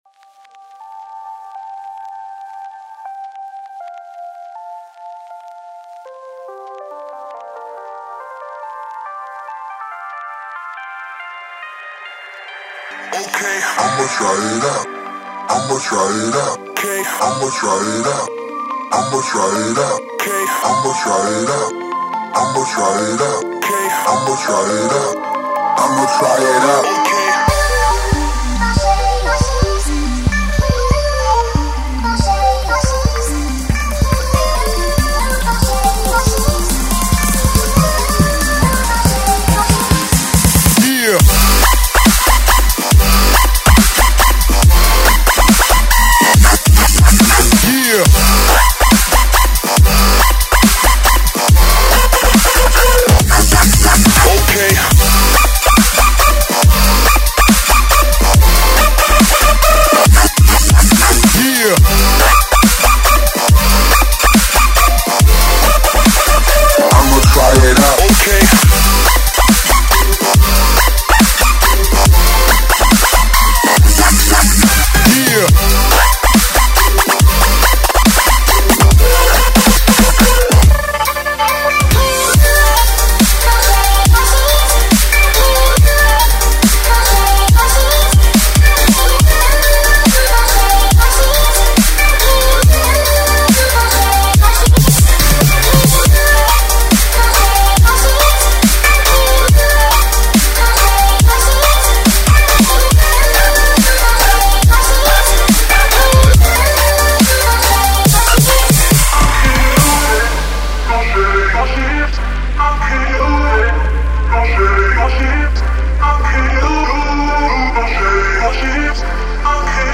داب استپ